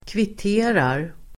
Uttal: [kvit'e:rar]
kvitterar.mp3